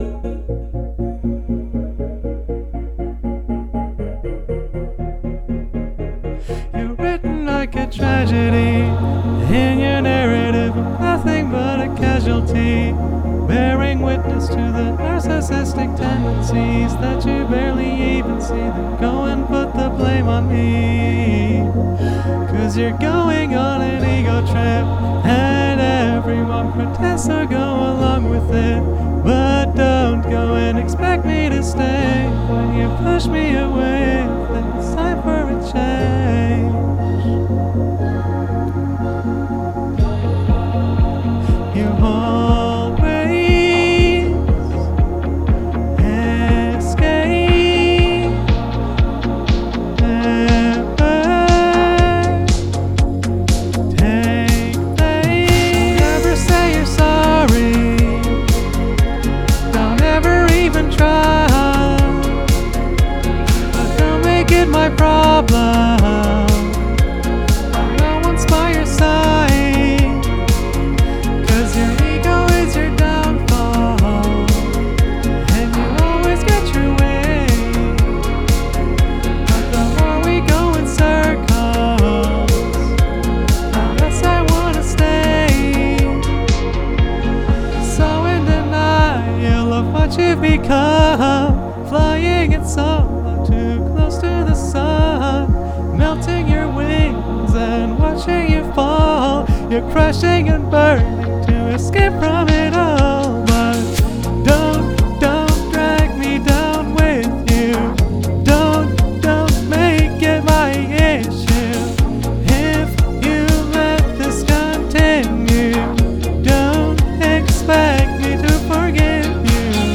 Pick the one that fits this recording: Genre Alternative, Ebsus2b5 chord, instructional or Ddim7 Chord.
Genre Alternative